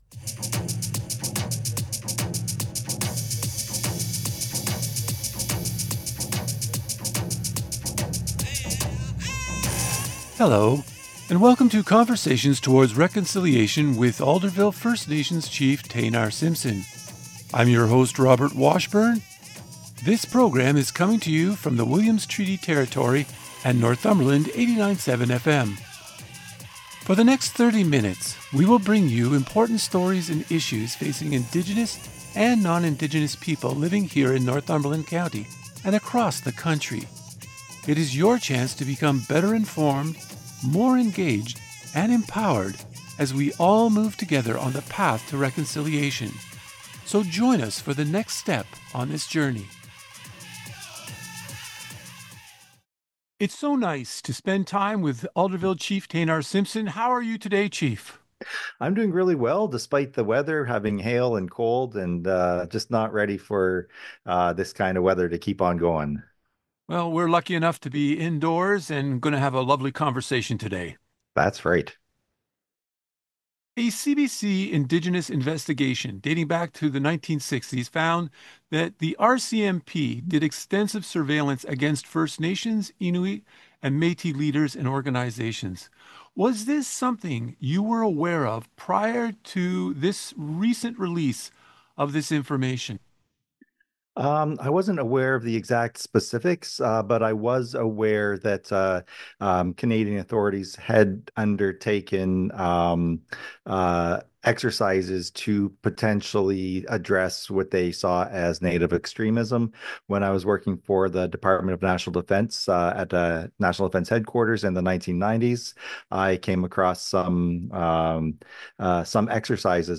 In this interview, Simpson talks about RCMP surveillance of Indigenous leaders, as well as the Ontario First Nations Child and Family Services Settlement, and the Indian Act’s impact on Indigenous communities.